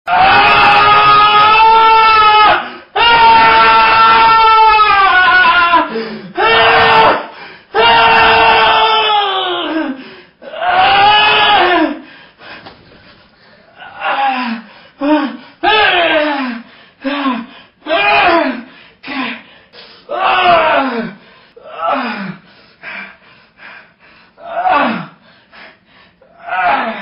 Дикий мужской крик (Wild men cry)
Отличного качества, без посторонних шумов.